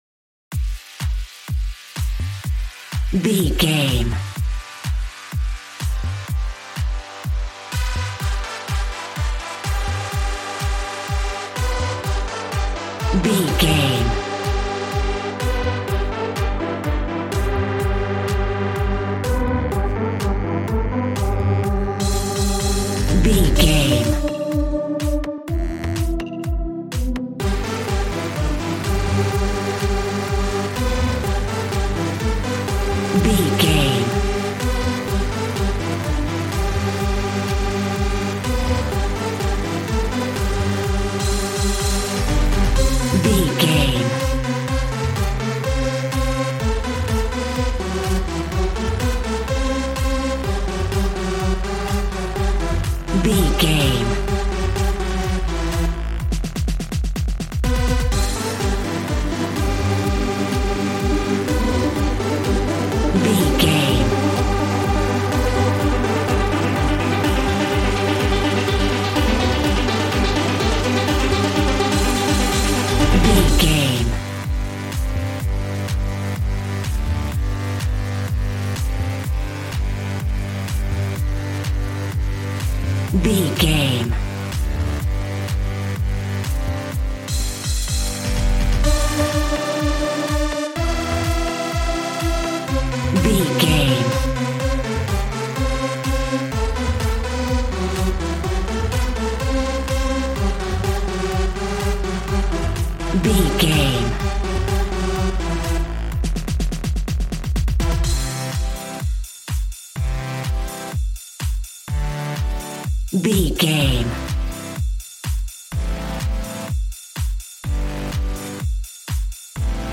Ionian/Major
Fast
uplifting
lively
groovy
synthesiser
drums